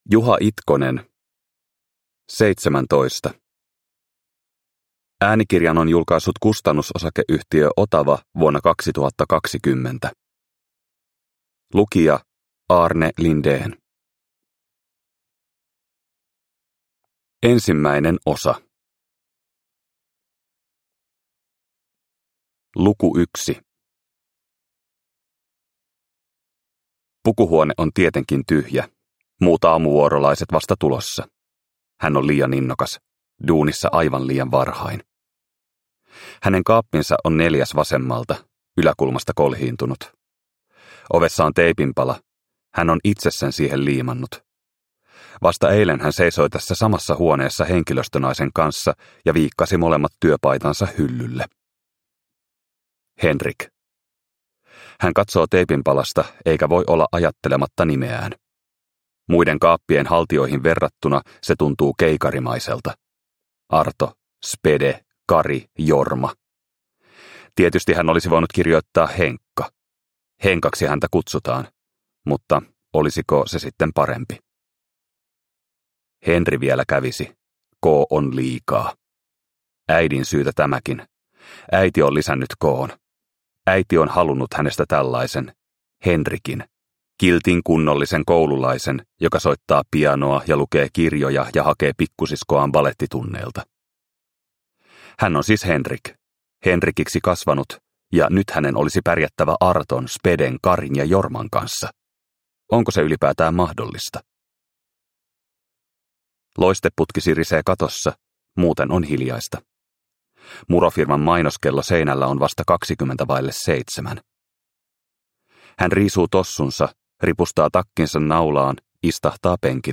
Seitsemäntoista – Ljudbok – Laddas ner